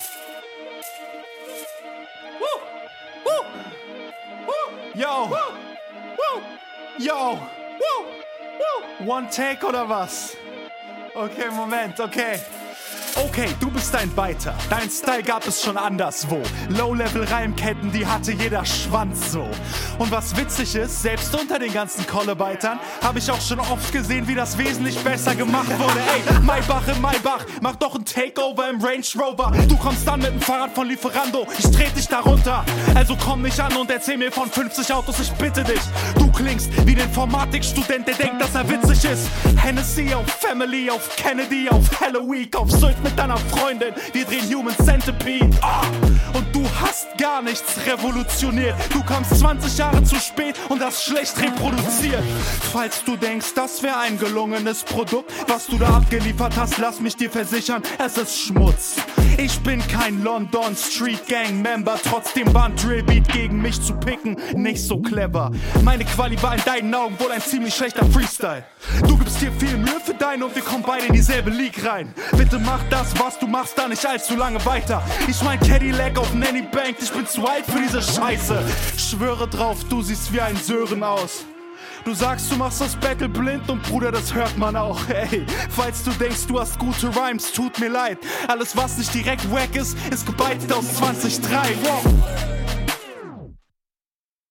Flow und Soundquality kommt wieder gut. Finde die Betonungen kommen hier auch ganz cool rüber.